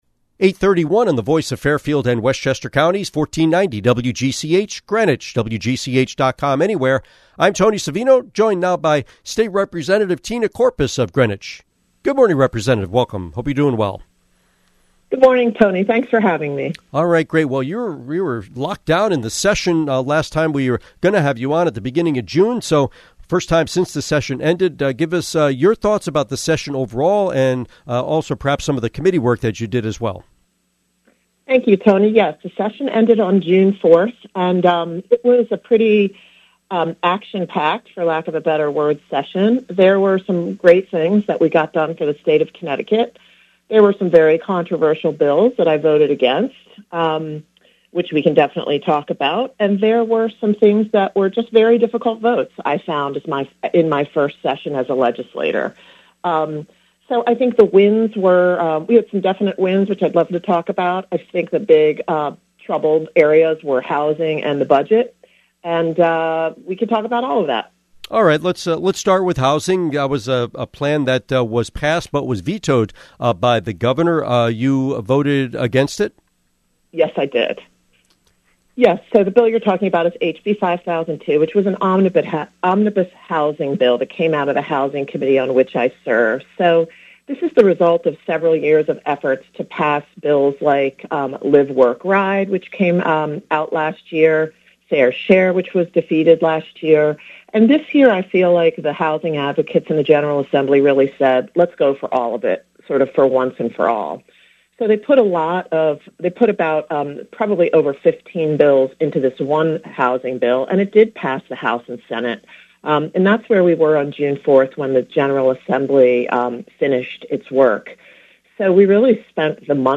Interview with State Representative Tina Courpas